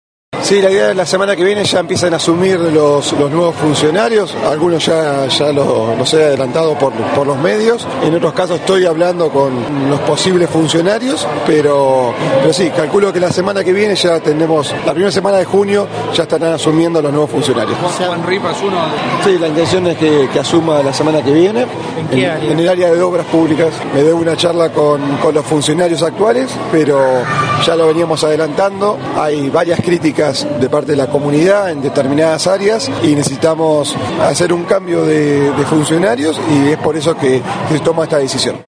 Luego de participar del acto por el 25 de Mayo que se realizó en la Escuela N° 24, el intendente Matías Taccetta formuló declaraciones a la prensa, confirmando que Juan Ripa se incorporaría al área de Obras Públicas y que Walter Torres estaría a cargo de las áreas de Turismo, Cultura y Deportes.